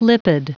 Prononciation du mot lipid en anglais (fichier audio)
Prononciation du mot : lipid